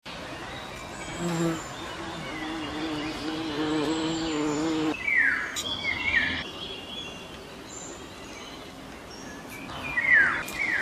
Waldgeräusch 7: Waldvogel, Waldvögel und Hummel / forest sound 7: forest bird, forest birds and bumblebee